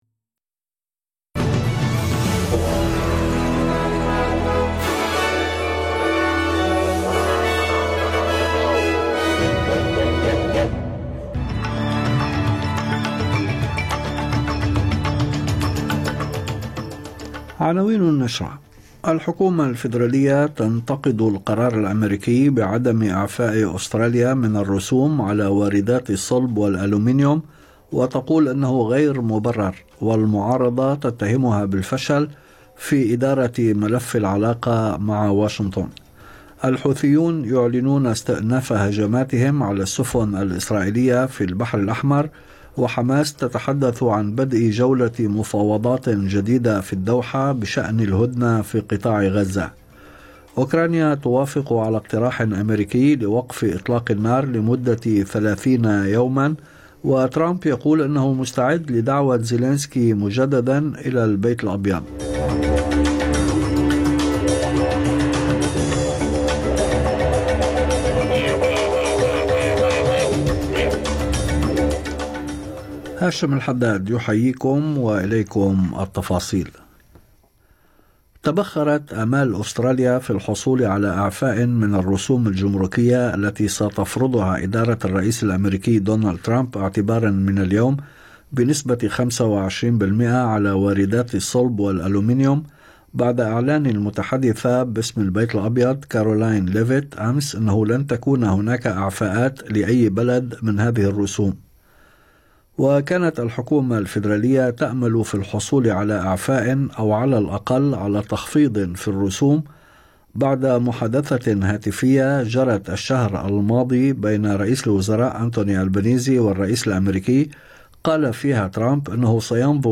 نشرة أخبار المساء 12/3/2025